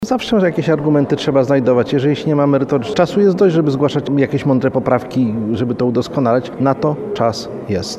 Jak podkreśla radny Prawa i Sprawiedliwości Krzysztof Żochowski, to nie jest ostateczna decyzja dotycząca osób niepełnosprawnych i projekt będzie konsultowany.